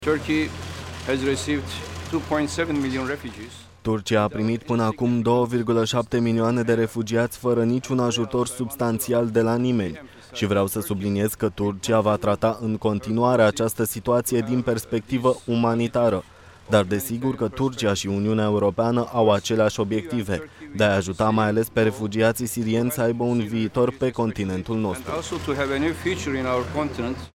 Aflat la Bruxelles, premierul turc, Ahmet Davutoglo, a declarat că scopul discuțiilor de astăzi este de a asigura un viitor mai bun refugiaților sirieni, în Uniunea Europeană.